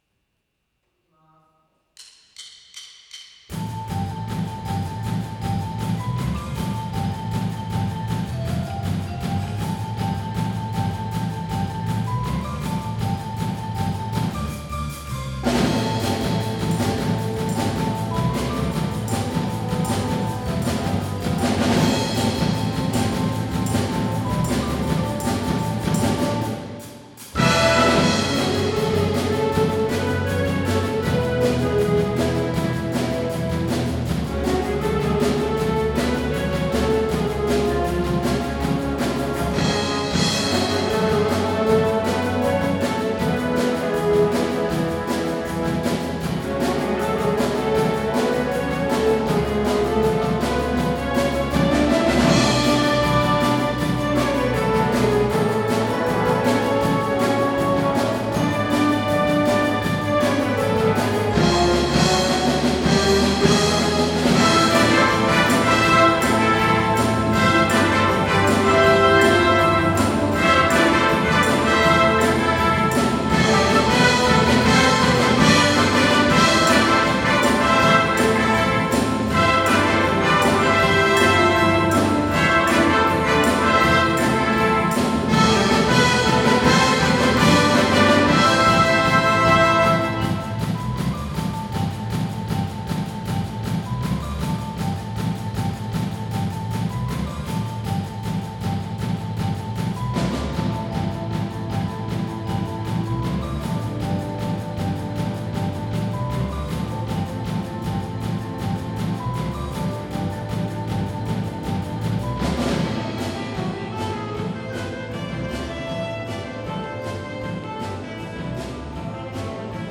ホールで楽しむ日 12月　三重県文化会館大ホール
とある理由から、なんと三重県文化会館の大ホールで練習できることになりました。
そして、今日の目的といえば、 大好きな曲を録音することです。